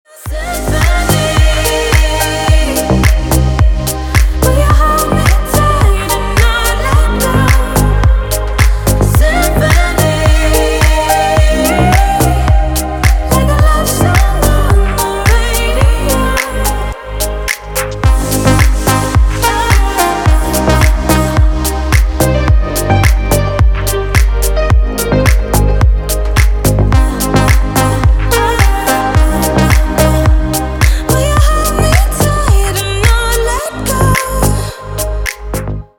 Красивый ремейк на рингтон
Красивые мелодии на телефон, мелодичные рингтоны Качество